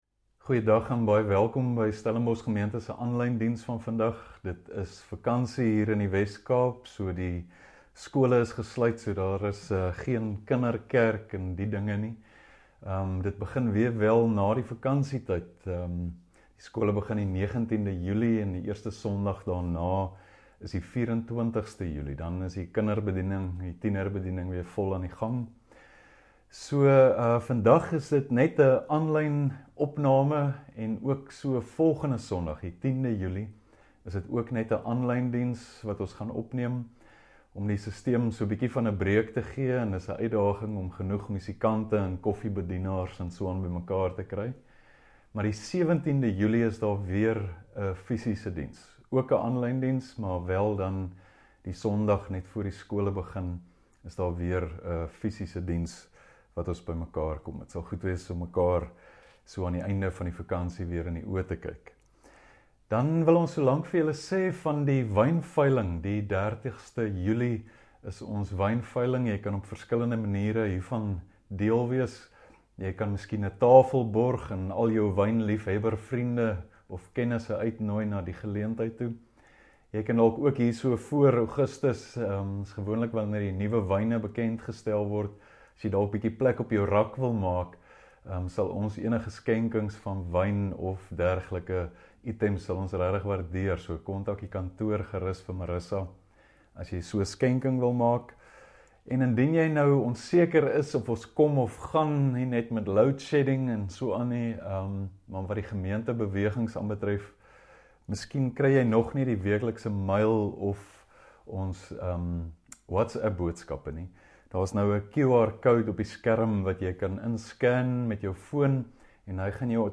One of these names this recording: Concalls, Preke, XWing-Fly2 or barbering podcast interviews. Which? Preke